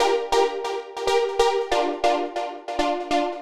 Index of /musicradar/future-rave-samples/140bpm